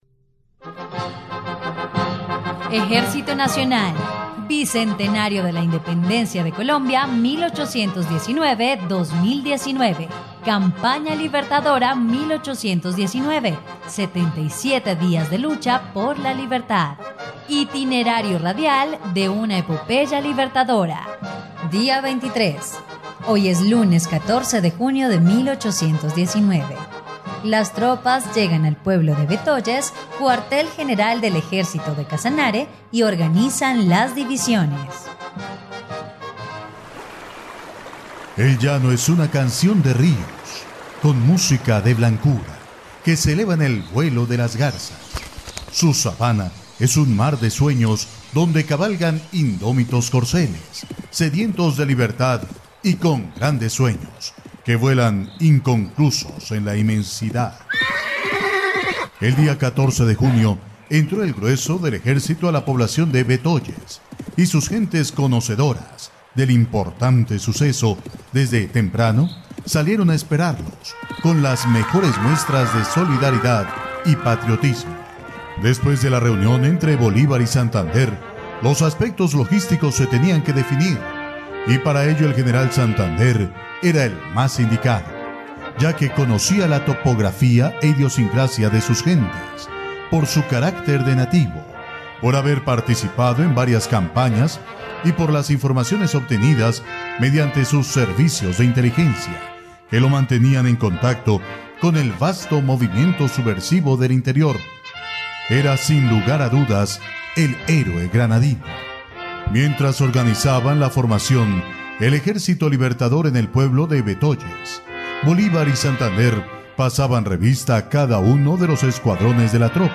dia_23_radionovela_campana_libertadora.mp3